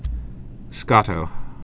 (skŏtō, skōttō), Renata Born 1934.